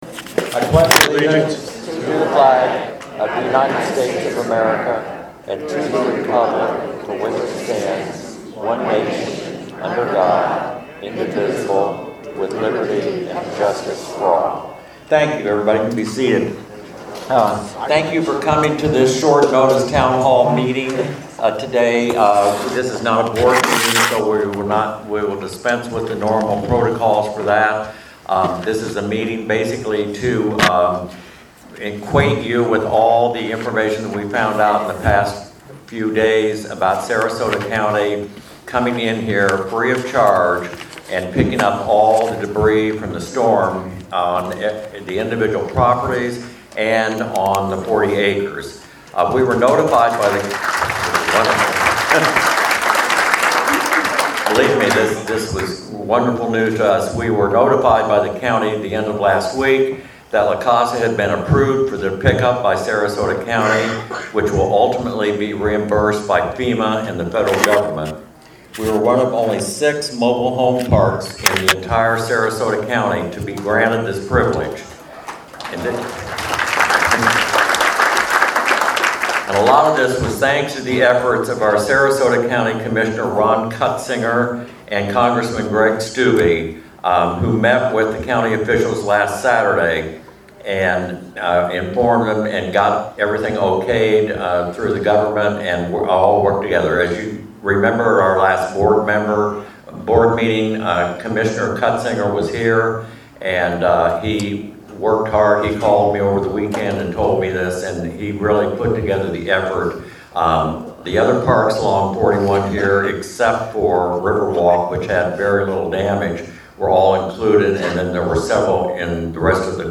La_Casa_Town_Hall_Meeting_11-9-22.MP3